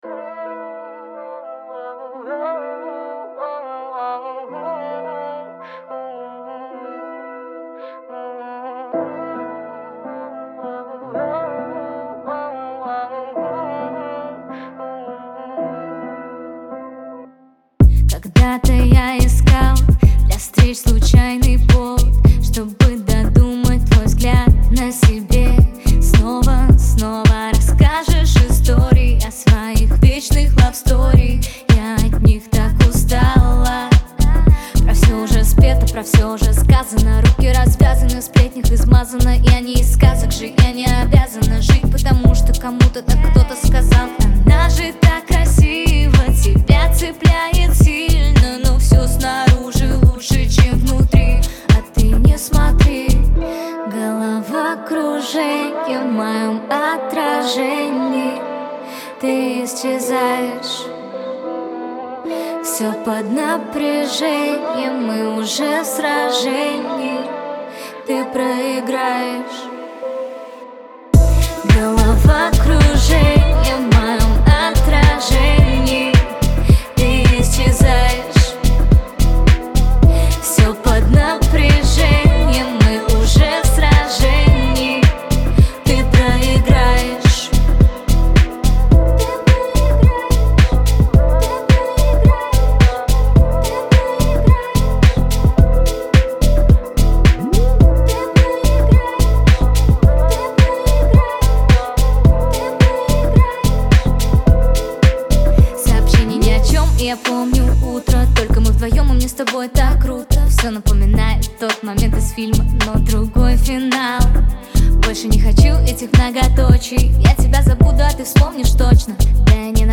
мощная поп-рок композиция